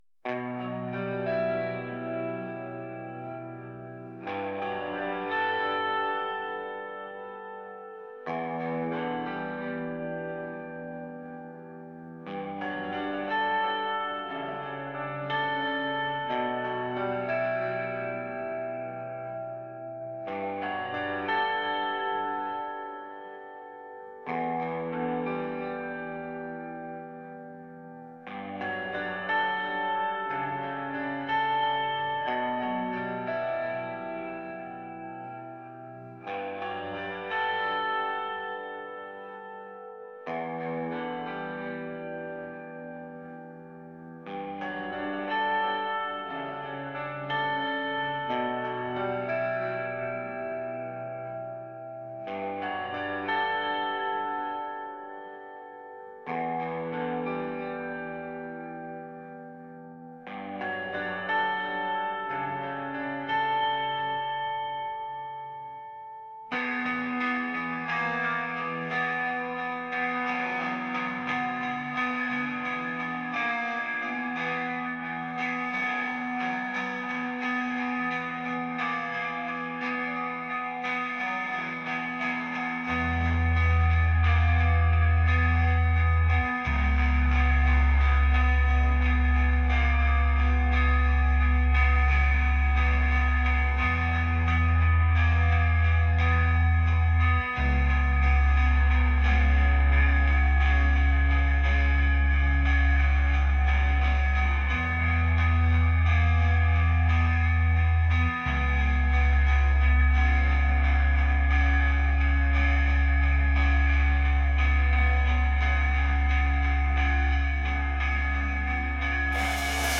alternative | rock | atmospheric